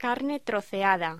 Locución: Carne troceada
voz